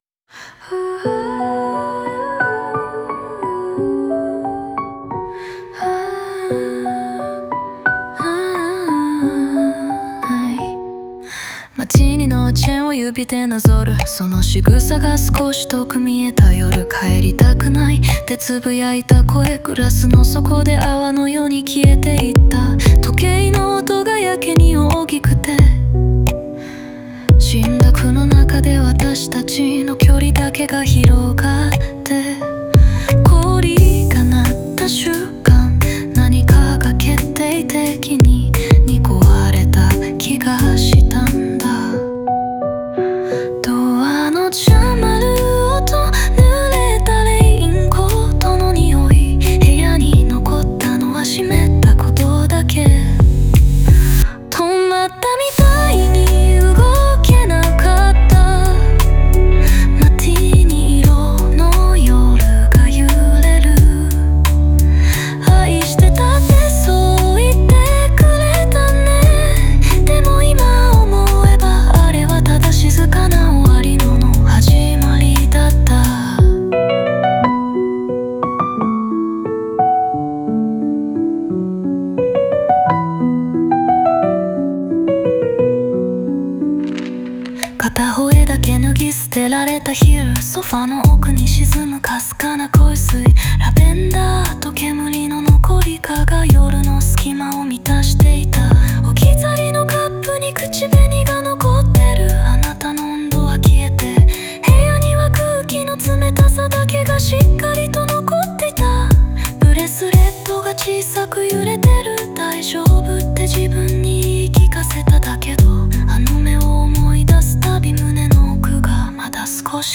沈黙や物の気配が感情の代弁となる、余韻の深いダークバラードです。